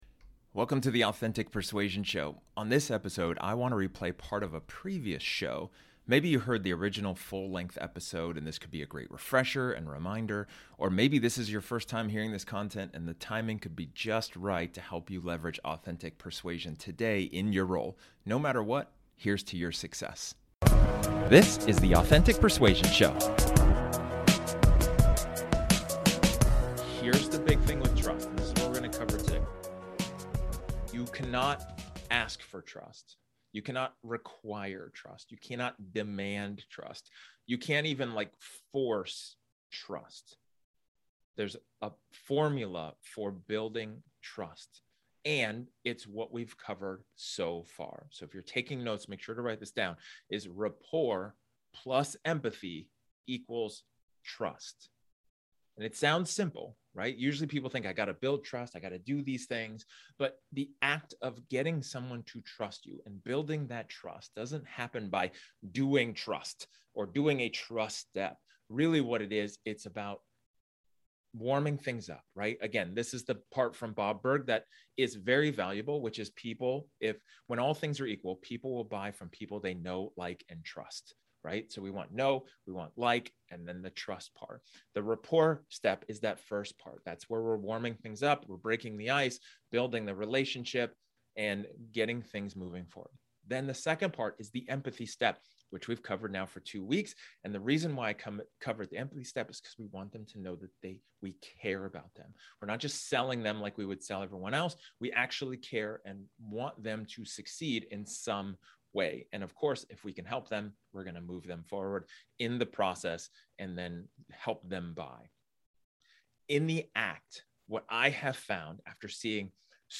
This episode is an excerpt from one of my training sessions where I talk about the formula of trust.